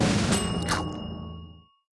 Media:Fast_Food_Tank_004.wav 部署音效 dep 局内选择该超级单位的音效